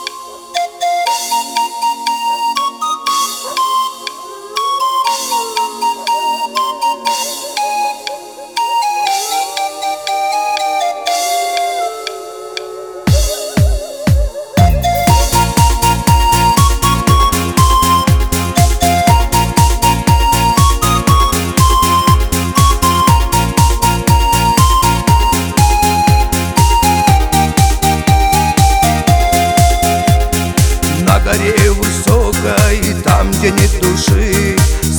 Жанр: Шансон / Русские
# Russian Chanson